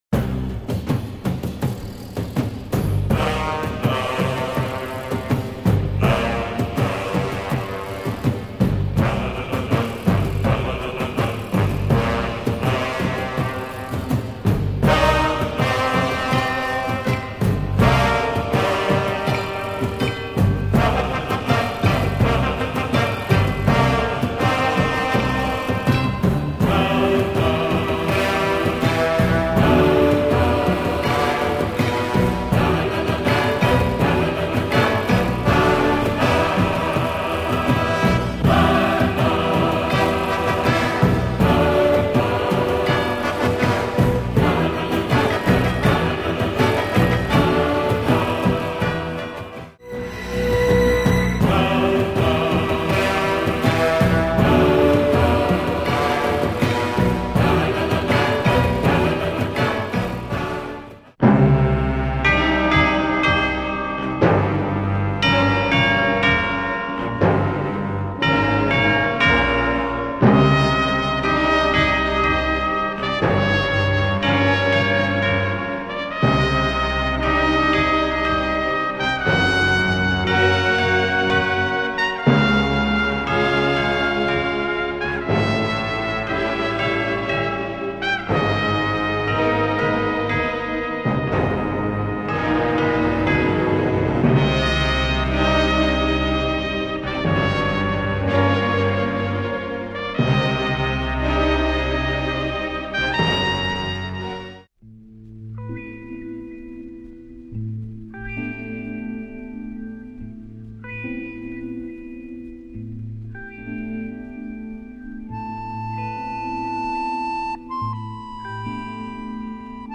it's a TV score he wrote in the mid 70's
Lots of samples / drama sound, here !